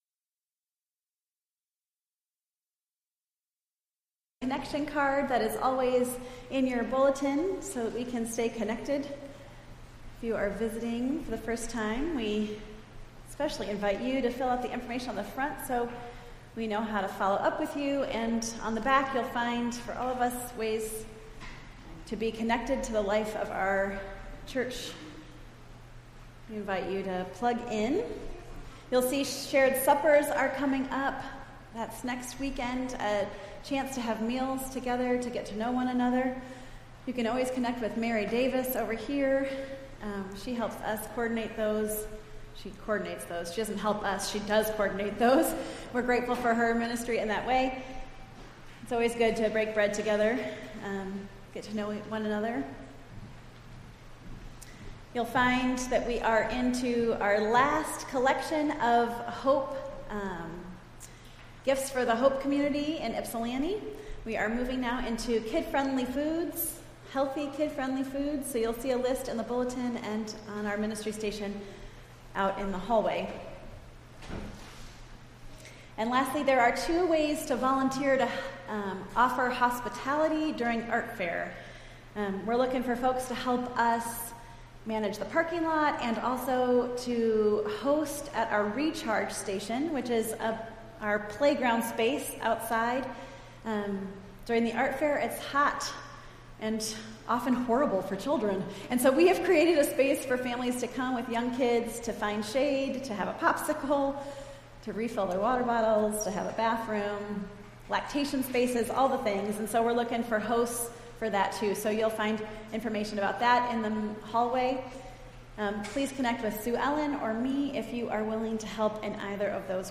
Entire July 2nd Service